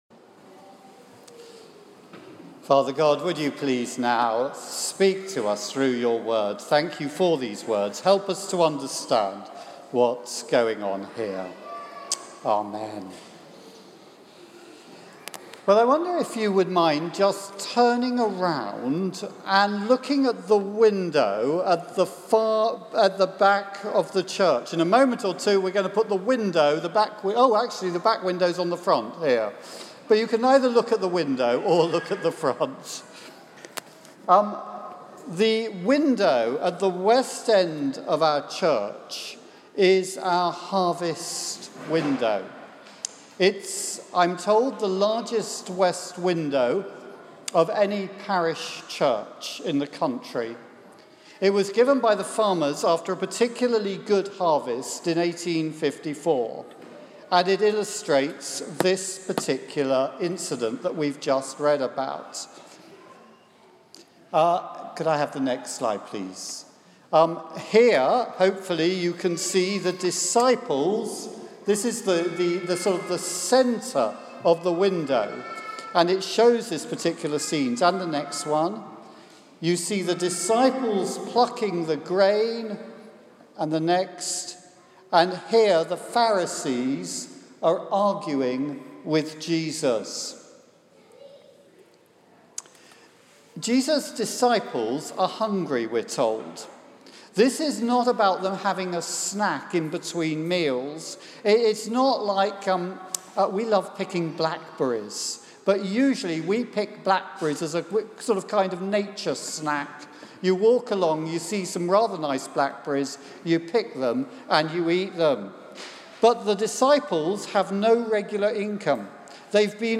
A harvest talk on Matthew 12.1-8 at St Mary's. Preacher